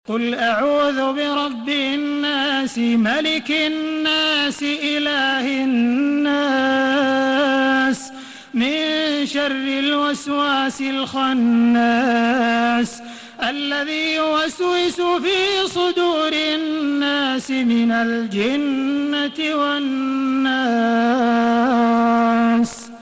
سورة الناس MP3 بصوت تراويح الحرم المكي 1432 برواية حفص
مرتل